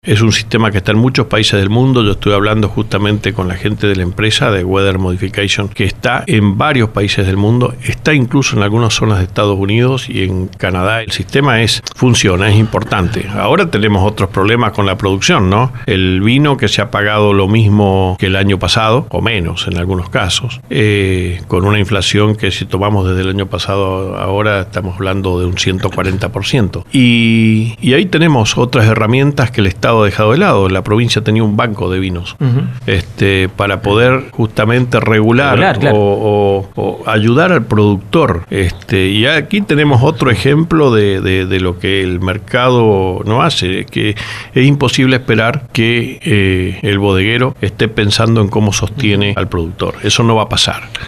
La falta de acompañamiento del Estado nacional, su economía, la autonomía municipal y la modernización del Estado fueron algunos de los temas sobre los que habló Omar Félix -intendente de San Rafael- en LV18, apenas unas horas más tarde de su discurso de apertura de sesiones ordinarias del Concejo Deliberante.